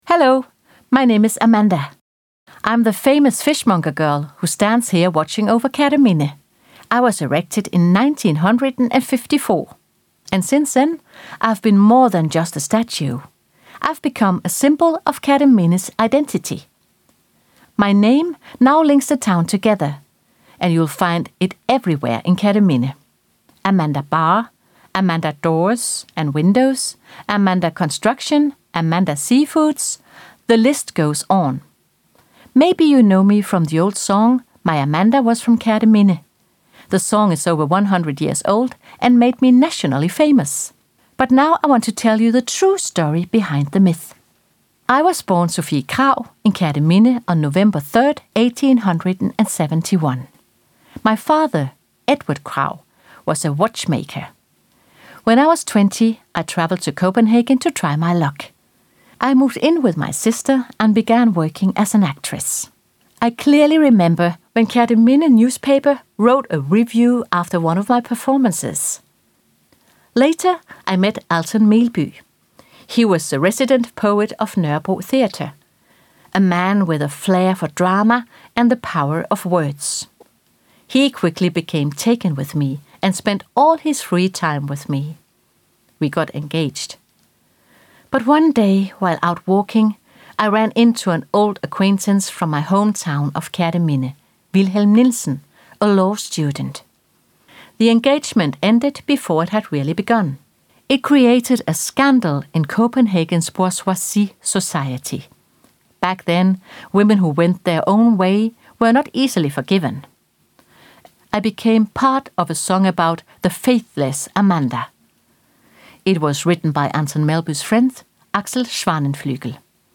The monologue is designed to be both entertaining and educational, lasting just a few minutes—perfect for a quick cultural moment during your visit.This innovative project invites everyone to experience public art in a fresh and personal way.